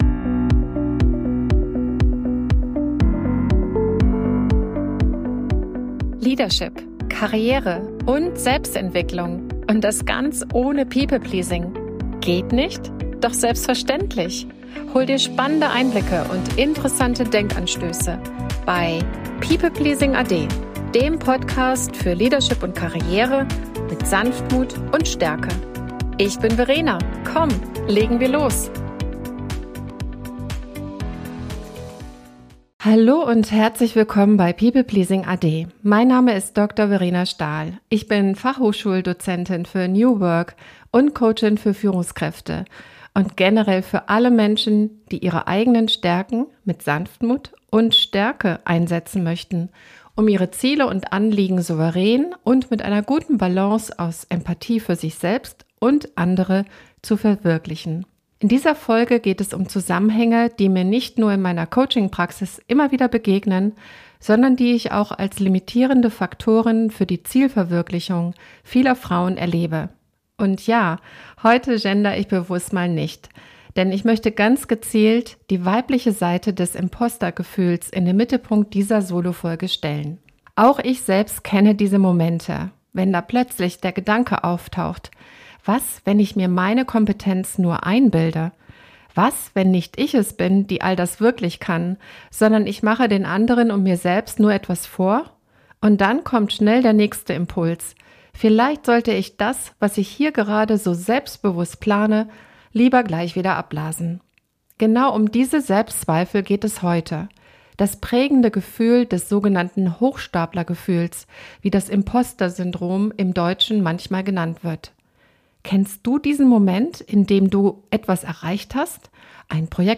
In dieser Solofolge spreche ich über das toxische Zusammenspiel von People Pleasing und Imposter-Gefühlen – und warum gerade Highperformer davon betroffen sind. Du erfährst, was hinter diesen Mustern steckt, welche Rolle verzerrte Maßstäbe, Social Media und Rollenbilder dabei spielen – und wie du dich Schritt für Schritt davon lösen kannst.